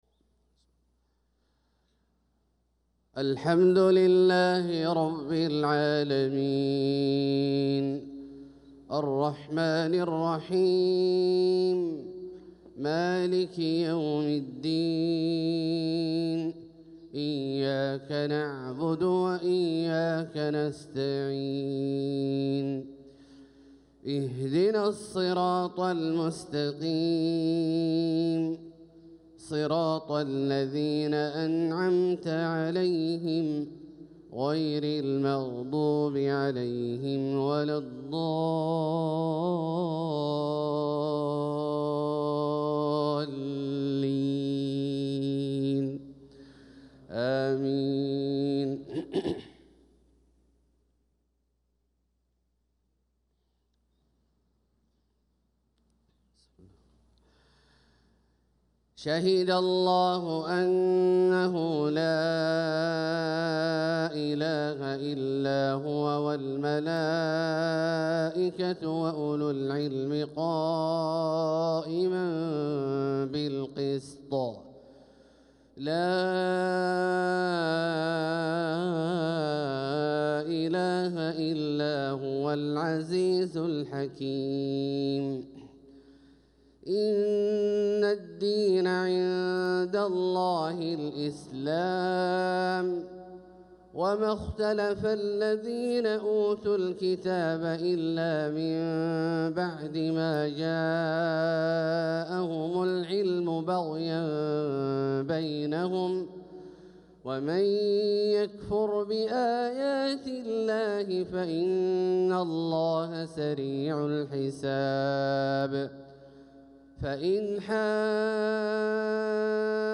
صلاة الفجر للقارئ عبدالله الجهني 21 صفر 1446 هـ
تِلَاوَات الْحَرَمَيْن .